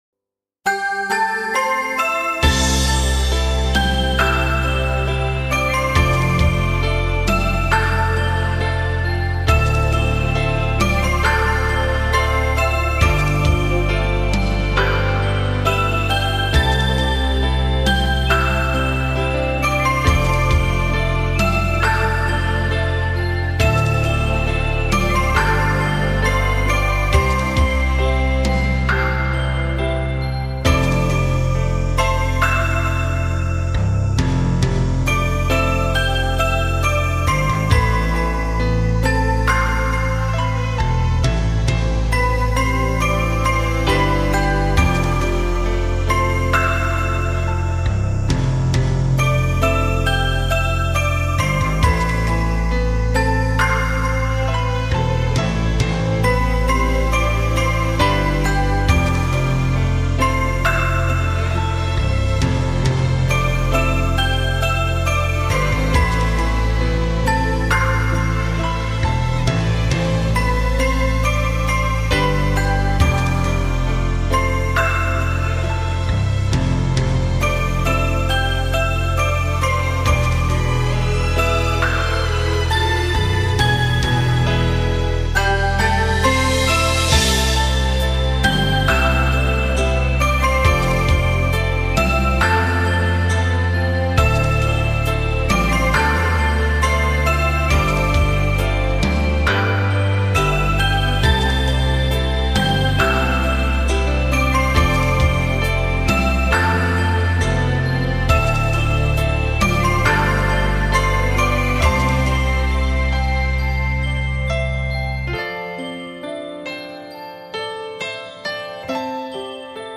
来自阿拉伯半岛， 印度和东南亚的新鲜声音。
那是绝对松弛、没有压力